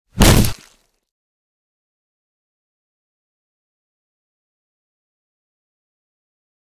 Bullet Hits Body With Wet Impact